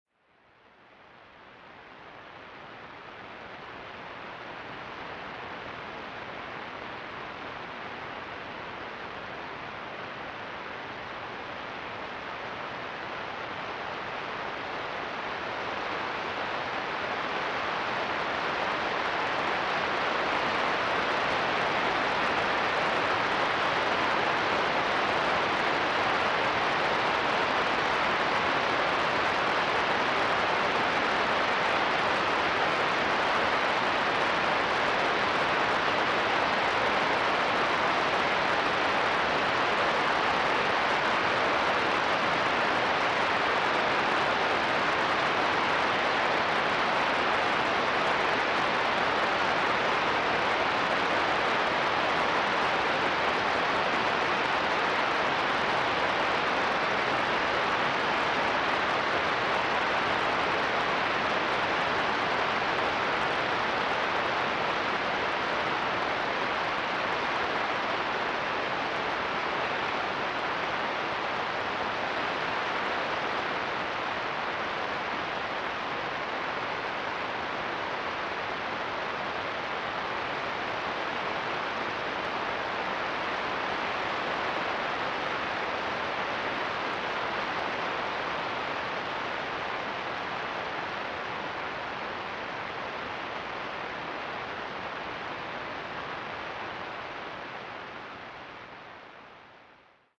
The flare also produced moderate Type II radio sweeps which were recorded on our radio telescope array at 21 MHz and 19 MHz.
Below: Type II Radio Sweeps at 21.1 MHz Listen to